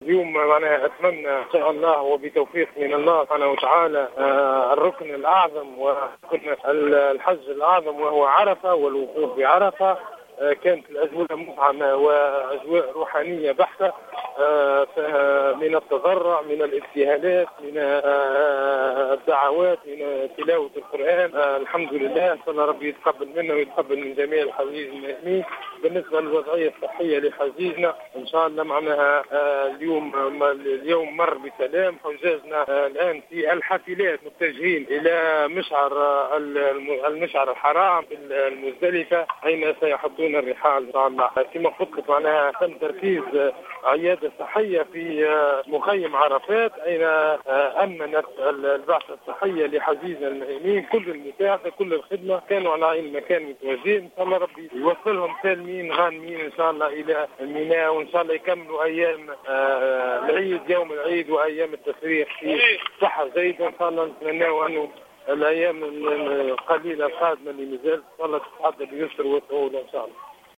في اتصال هاتفي من البقاع المقدسة